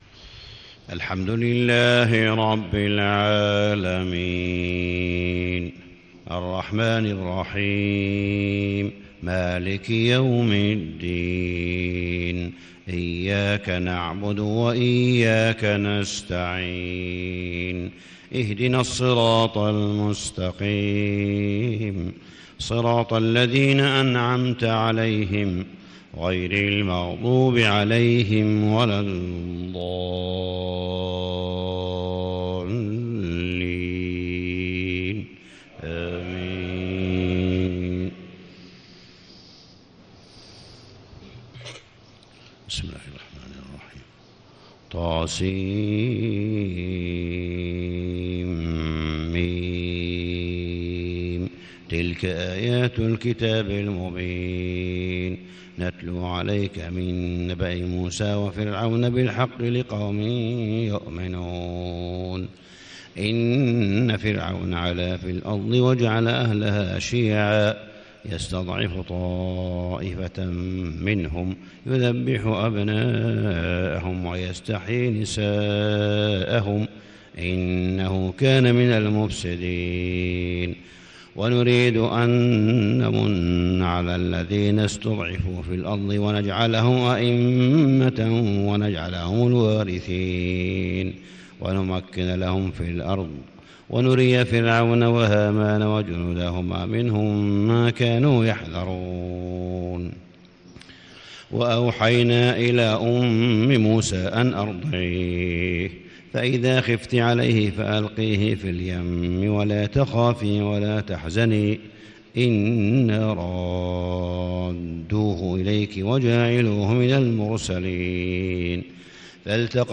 صلاة الفجر 6-6-1437هـ فواتح سورة القصص 1-22 > 1437 🕋 > الفروض - تلاوات الحرمين